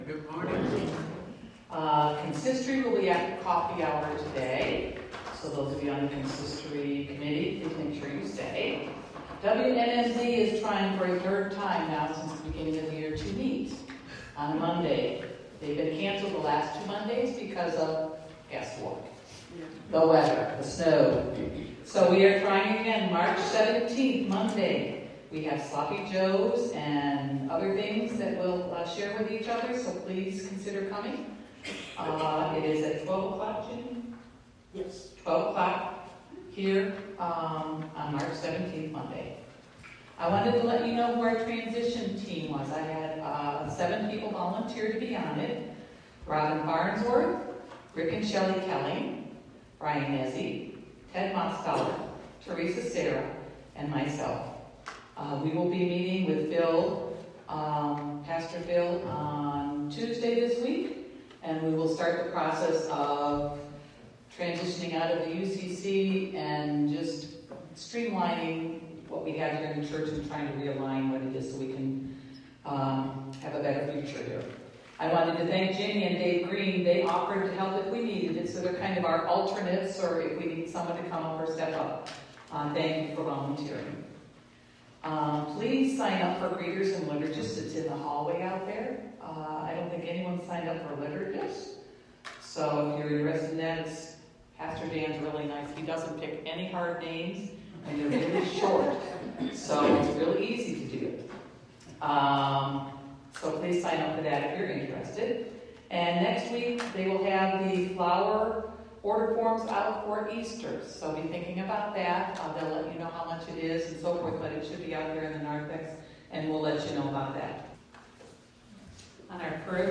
3-9-2025 Service at Mt. Zwingli